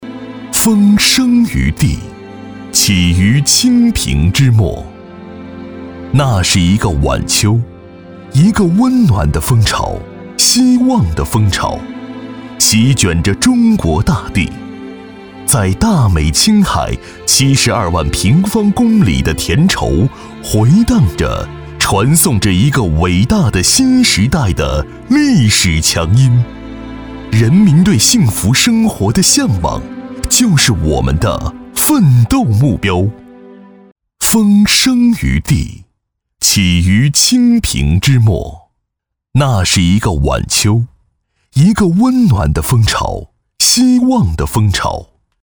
大气浑厚偏年轻男中音，年轻活力、清新感性。擅长专题，宣传片，mg动画，讲述，旁白等题材。作品：黄埔党建。